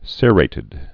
(sîrātĭd)